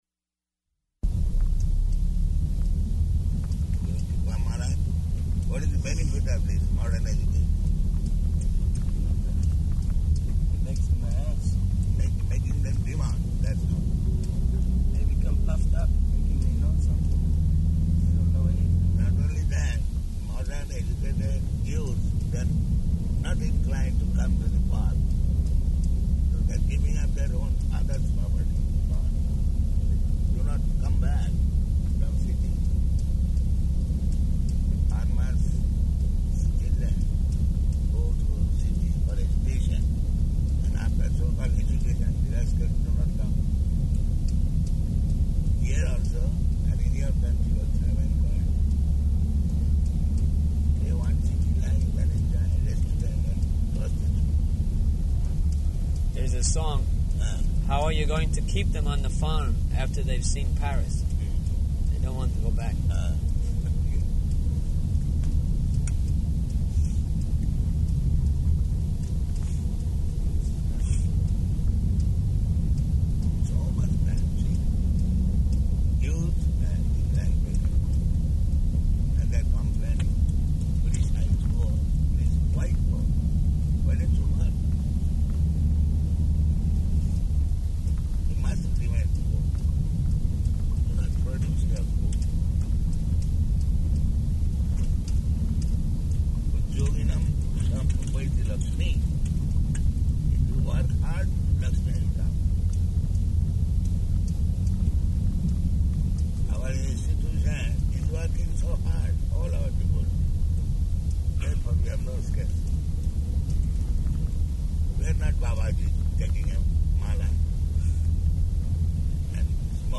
January 24th 1977 Location: Bhubaneswar Audio file